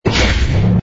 engine_pi_fighter_start.wav